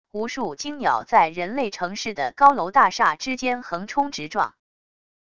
无数惊鸟在人类城市的高楼大厦之间横冲直撞wav音频